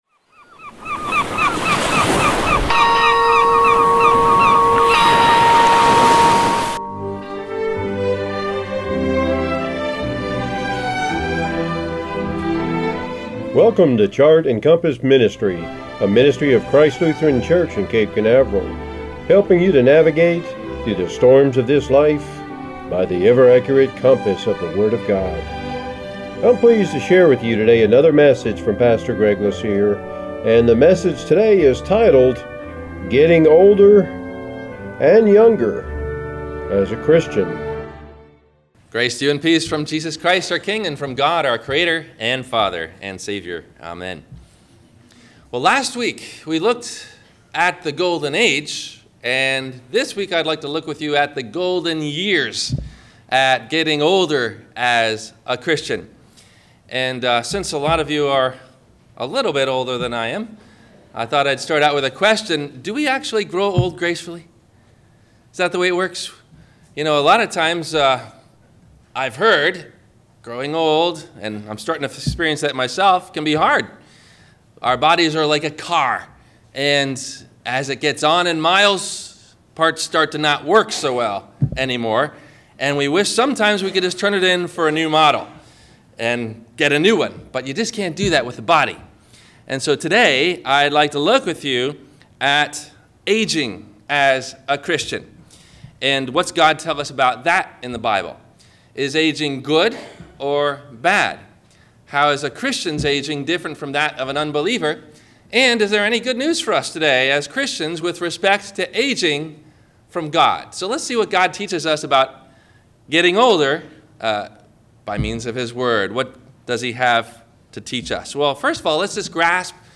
Questions asked before the Sermon message:
Have a Comment or Question about the Sermon?